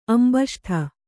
♪ ambaṣṭha